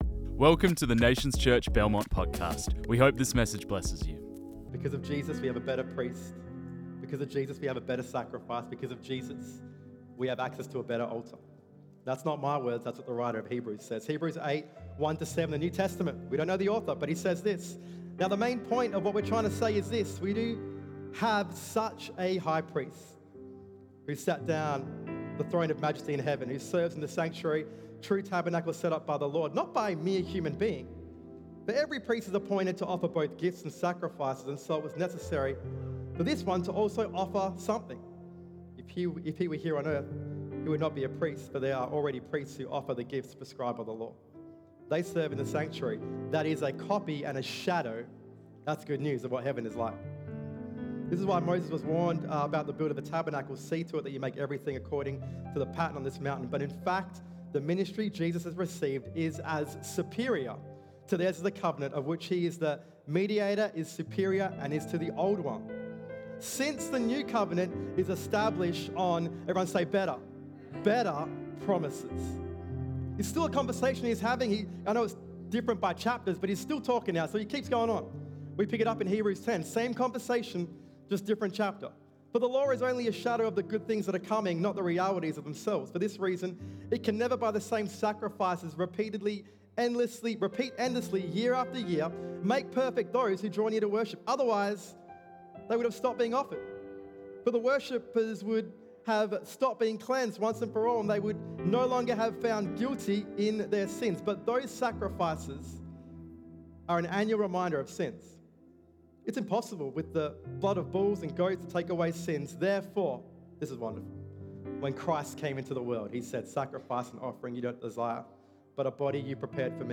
This message was preached on 27 July 2025.